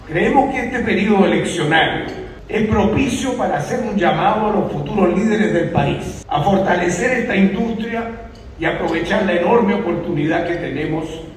Por su parte, el exministro del ramo, Sergio Bitar, llamó a proyectar la minería como un tema estratégico en el debate político.